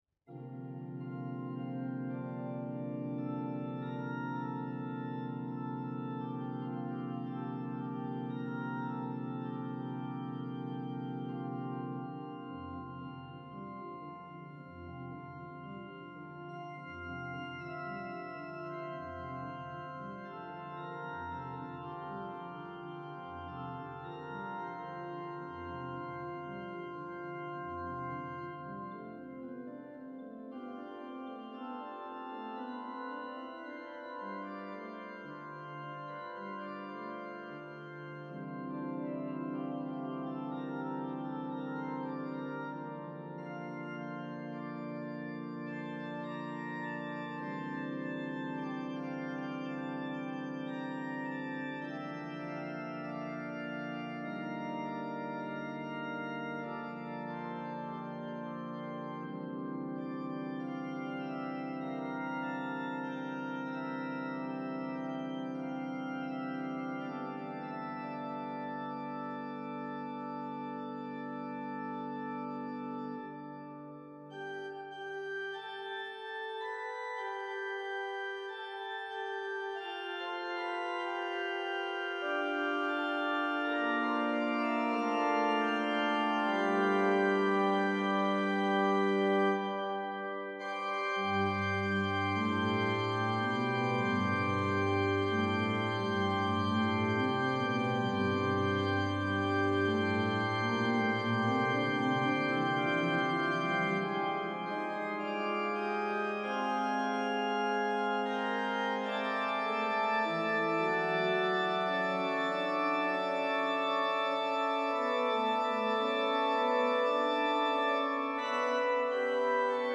organ Click to listen.